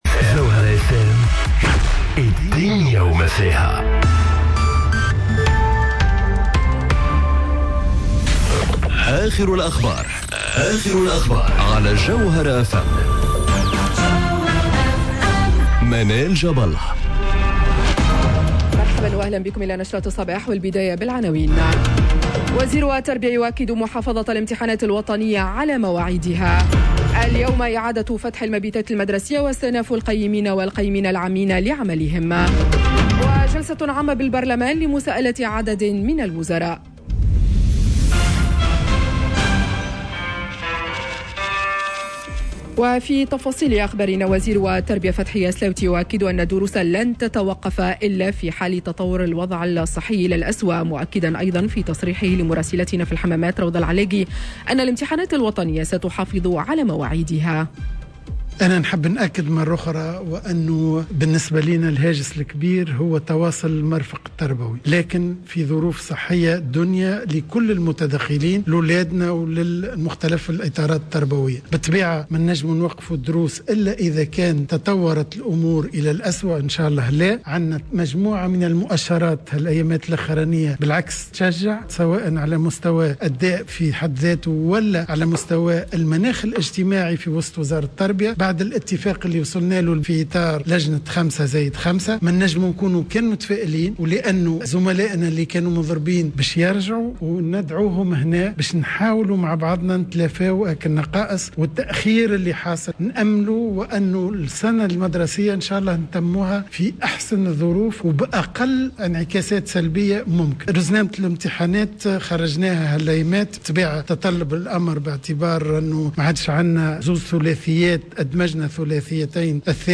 نشرة أخبار السابعة صباحا ليوم الإثنين 08 فيفري 2021